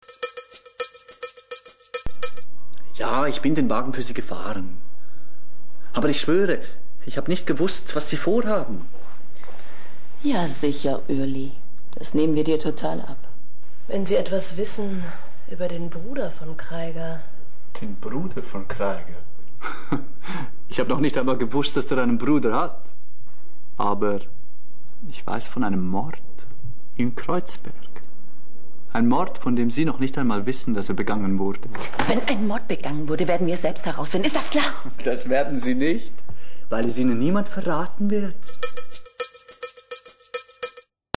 Sprecherdemos